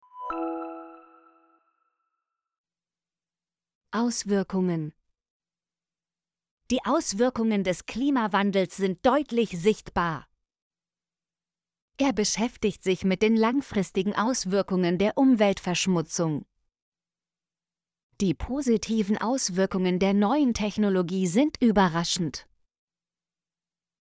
auswirkungen - Example Sentences & Pronunciation, German Frequency List